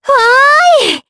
Leo-Vox_Happy4_jp.wav